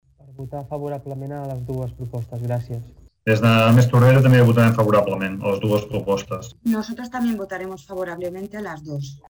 El Ple de l’Ajuntament de Tordera aprova la modificació de determinades condicions financeres de préstecs formalitzats amb el Fons de Finançament a les Entitats Locals i l’Adopció de les mesures necessàries per donar compliment al Pla d’Ajust.
Presenten el vot favorable Oriol Serra de la CUP, Xavier Pla de +Tordera i Miriam Rocabruna de Ciutadans.